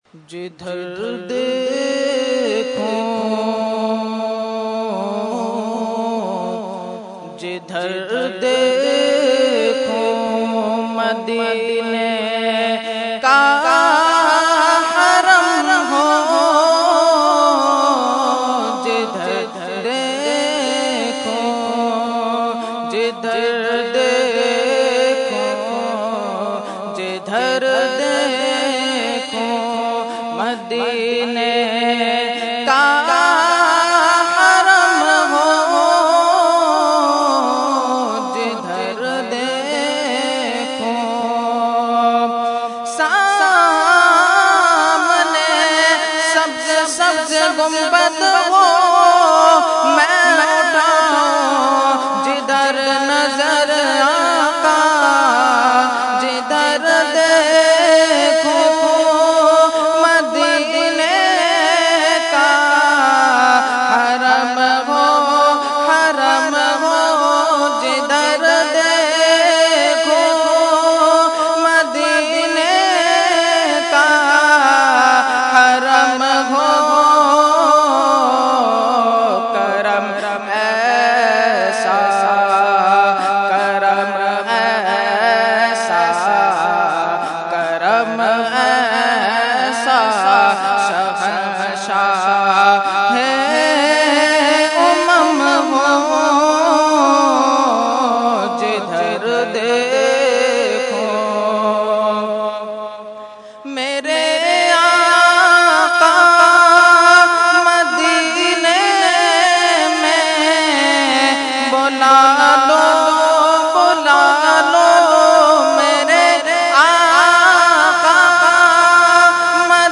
Category : Naat | Language : UrduEvent : Urs Ashraful Mashaikh 2014